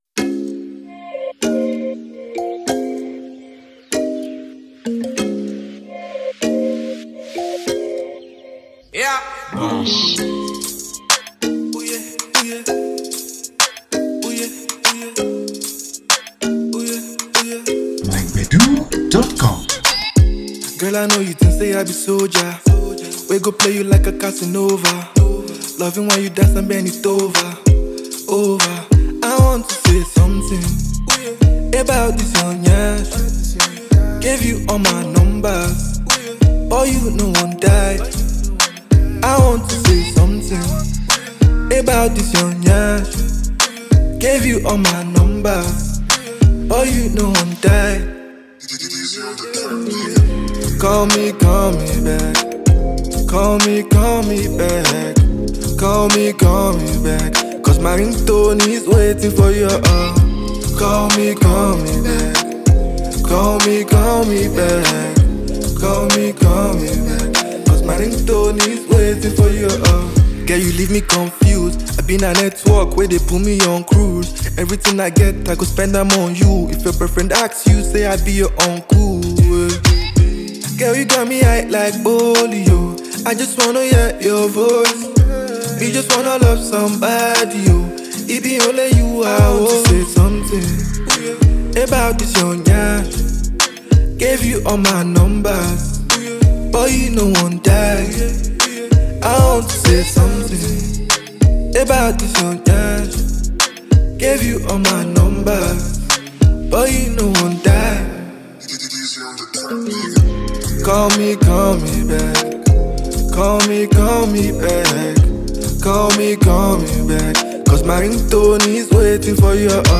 Sensational Afro Pop singer